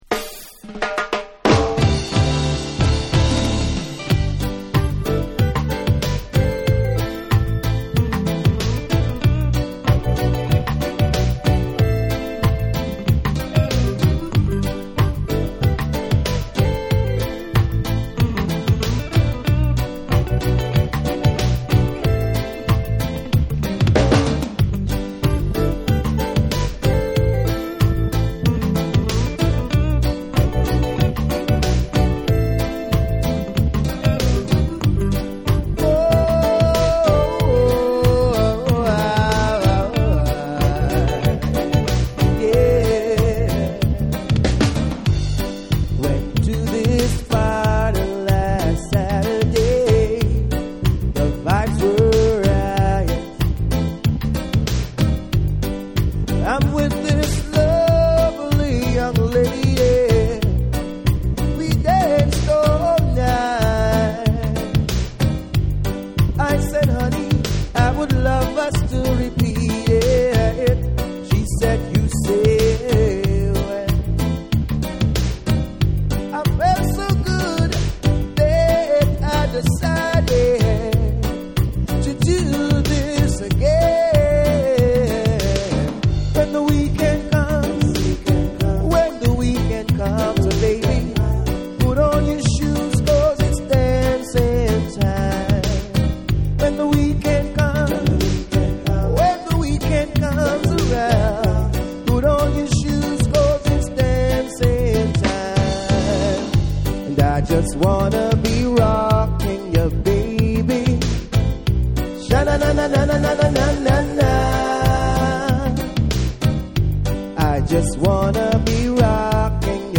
REGGAE & DUB / CLUB